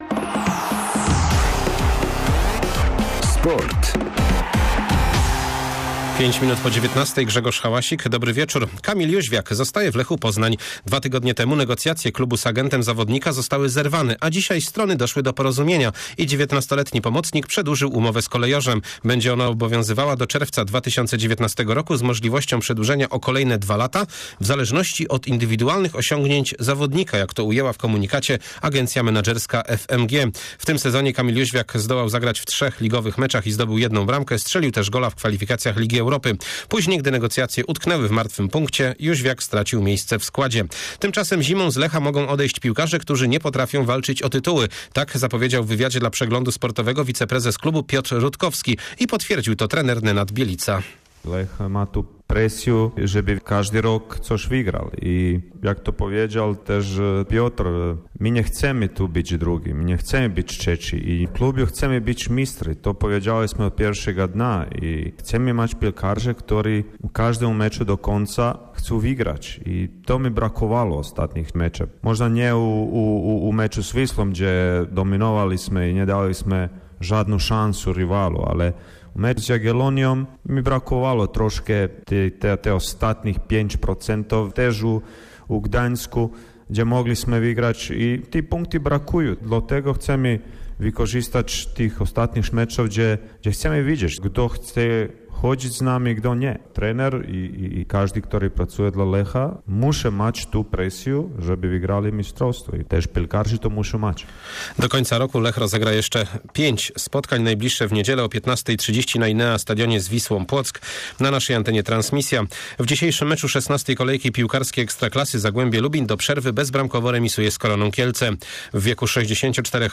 20.11 serwis sportowy godz. 19:05